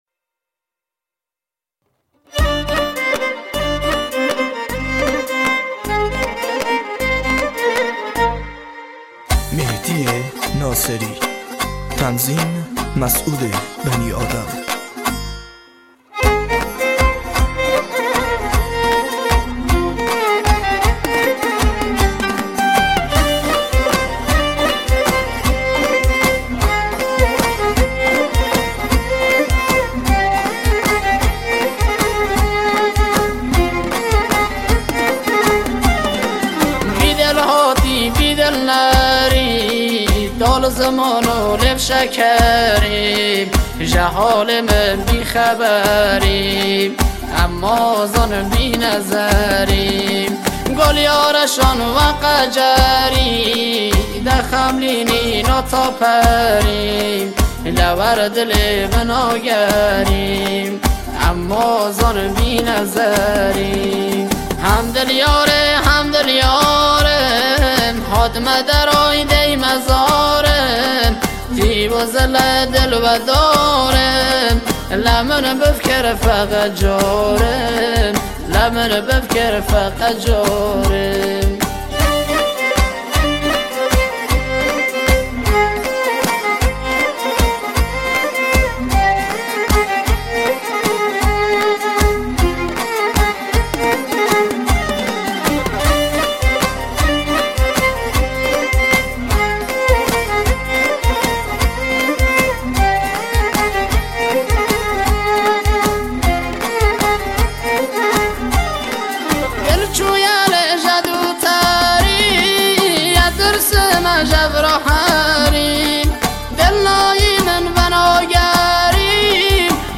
دانلود آهنگ کرمانجی
آهنگ مشهدی